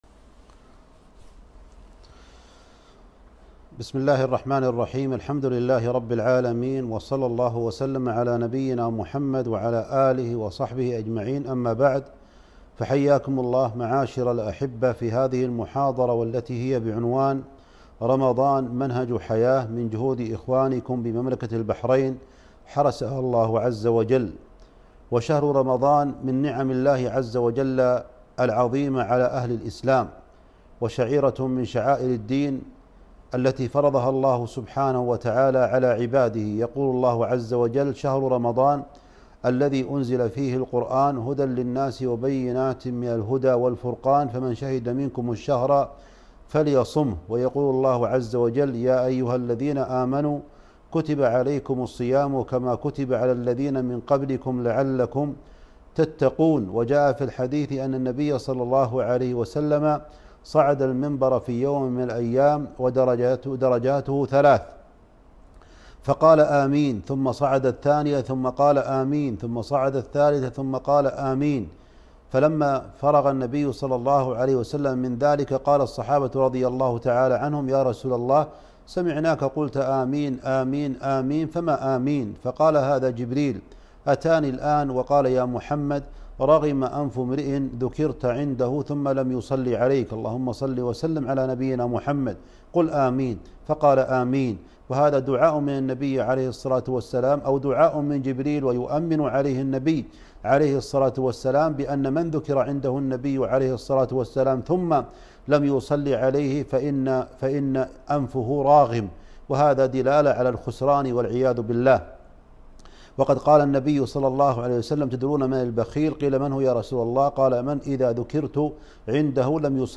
كلمة - رمضان منهج حياة